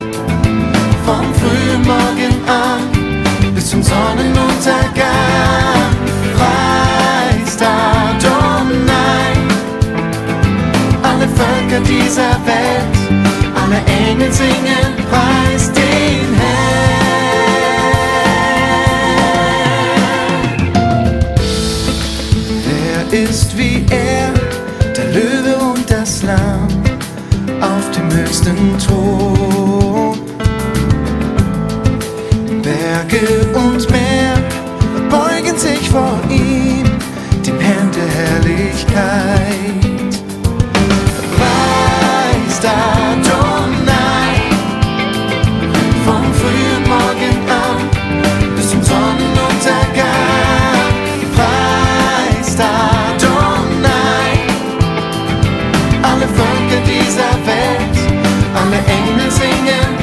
Sanft und persönlich.
• Sachgebiet: Praise & Worship
Wunderschön !!!!! 12 gesungene Gebete !!